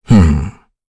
Kain-Vox_Think.wav